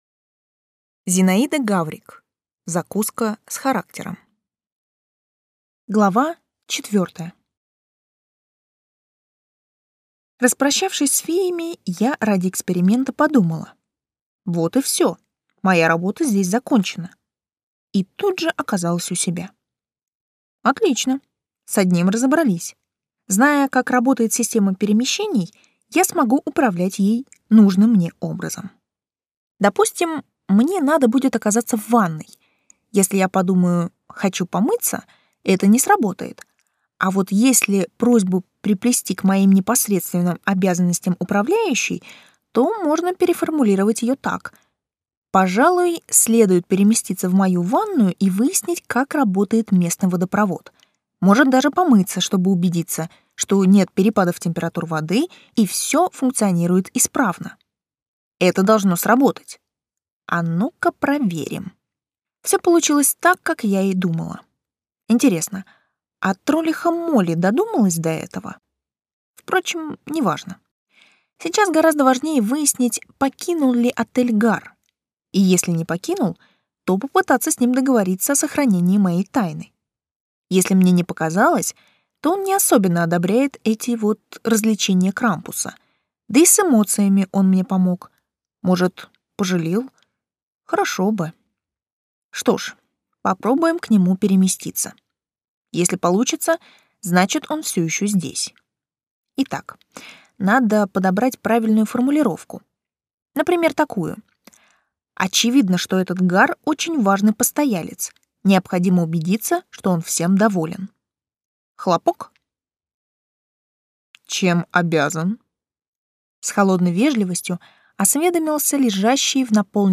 Аудиокнига Закуска с характером | Библиотека аудиокниг